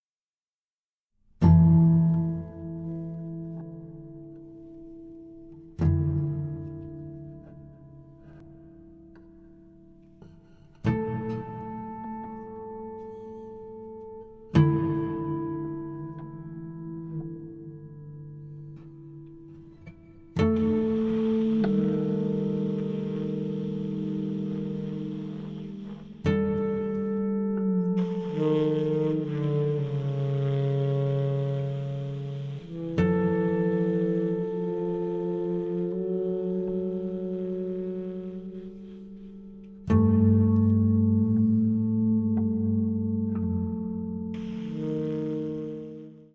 Tenor and Soprano saxophones, Alto flute